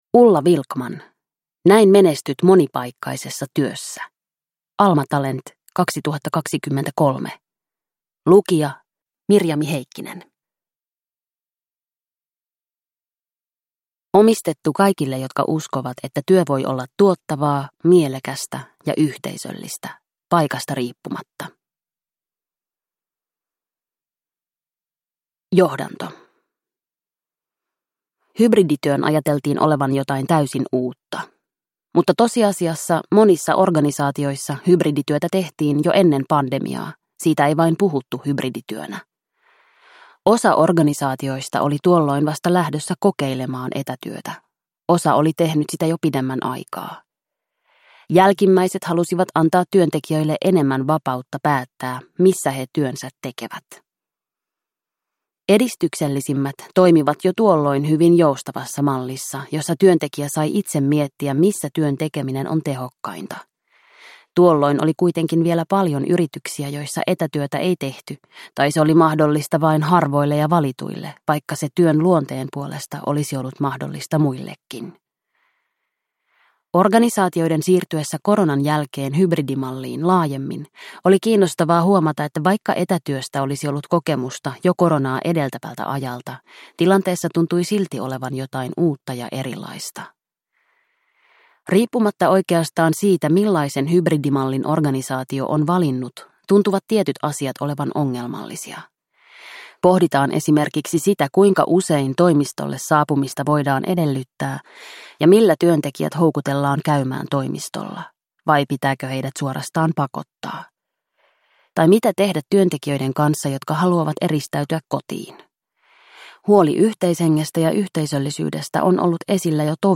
Näin menestyt monipaikkaisessa työssä – Ljudbok – Laddas ner